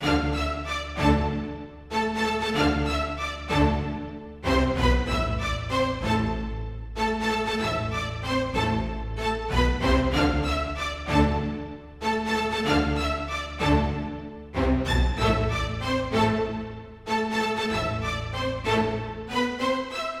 描述：嘻哈饶舌说唱|放克
标签： 贝司 弦乐器 合成器
声道立体声